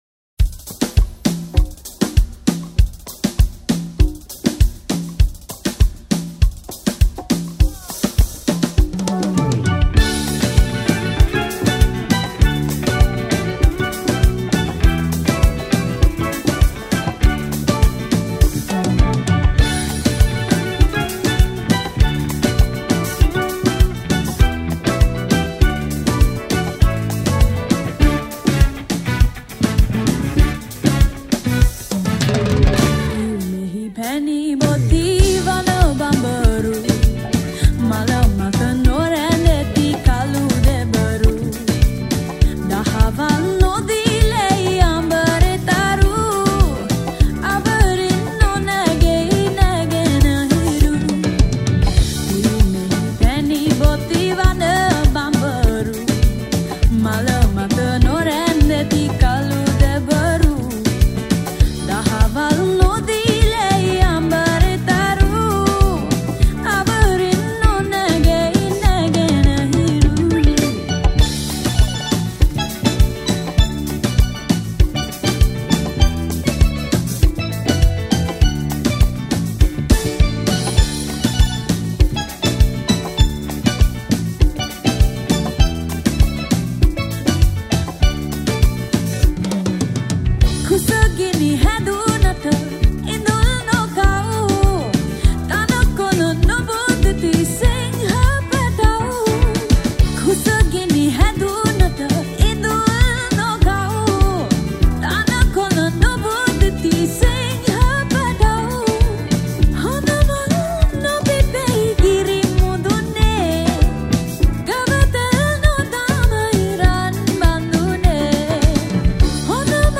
New Music Arrangement